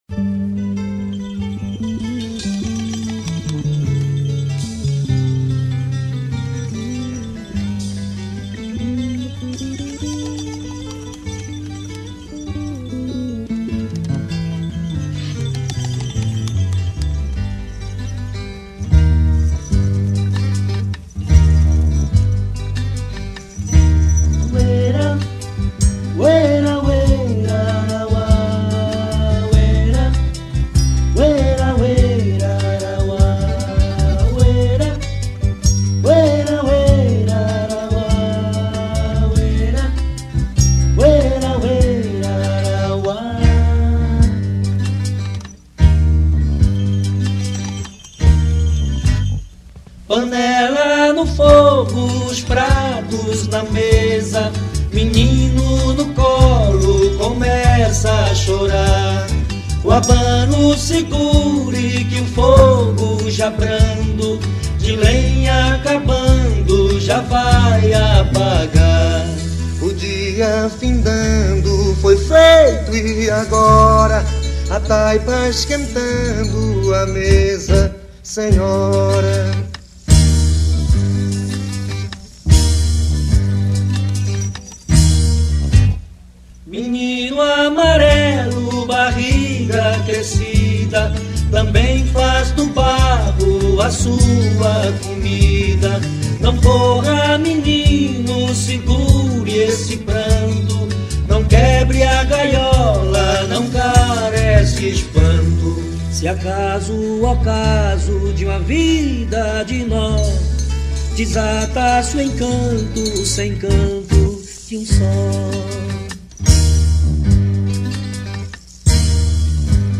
voz e violão
contrabaixo
viola e cavaquinho
flauta